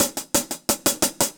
Index of /musicradar/ultimate-hihat-samples/175bpm
UHH_AcoustiHatB_175-02.wav